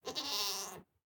Minecraft Version Minecraft Version snapshot Latest Release | Latest Snapshot snapshot / assets / minecraft / sounds / mob / goat / idle5.ogg Compare With Compare With Latest Release | Latest Snapshot